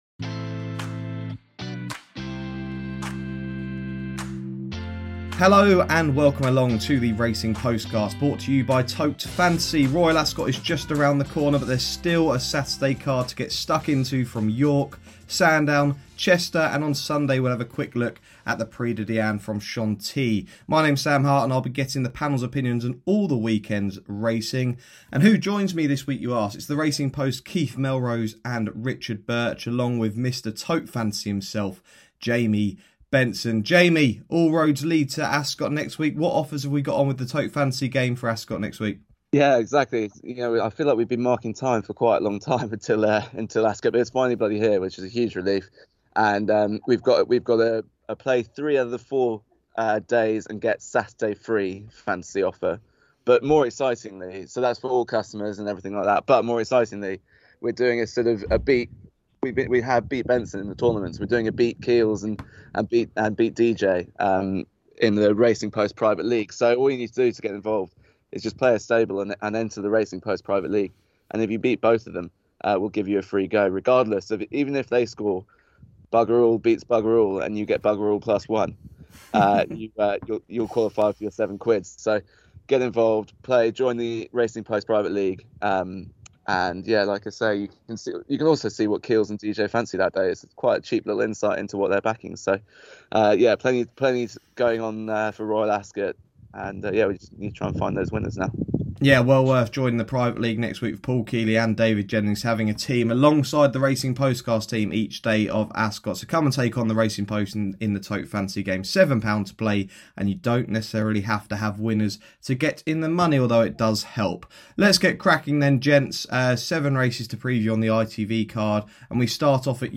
The show starts off with the panel discussing the four ITV races from York, where Israr is a warm favourite in the Listed Sky Bet Race To The Ebor Grand Cup Stakes.